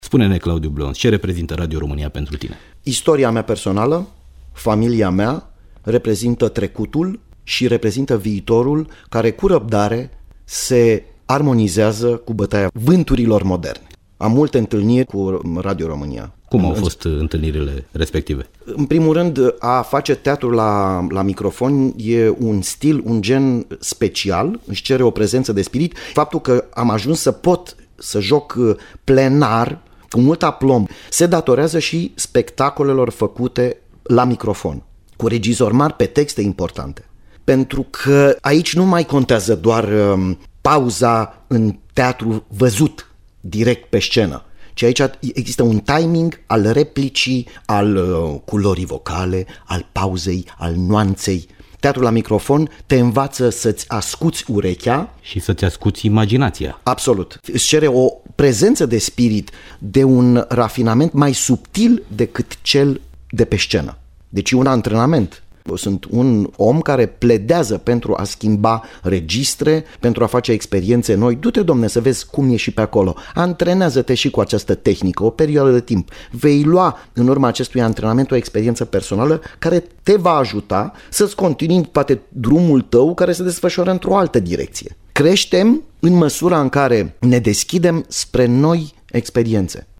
Iată un fragment din acest fascinant dialog, o pledoarie a faimosului actor pentru Radio România și teatrul radiofonic: